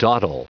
Prononciation du mot dawdle en anglais (fichier audio)